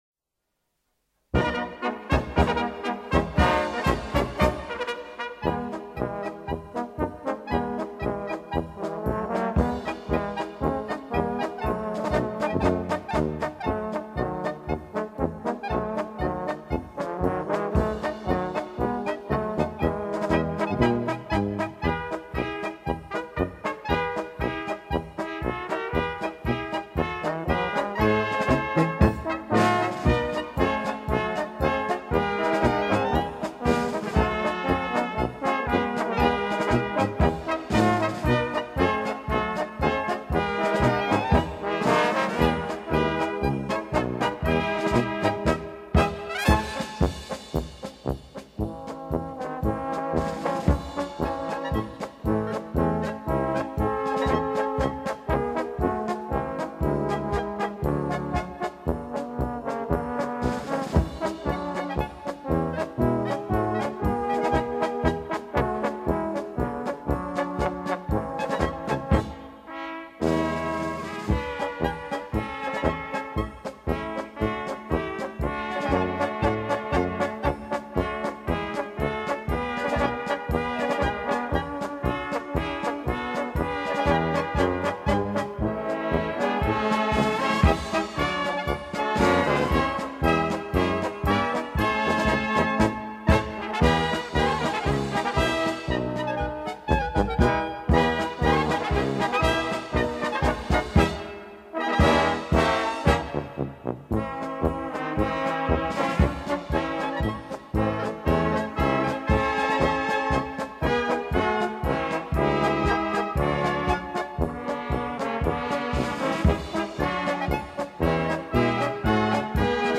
Orchesterversion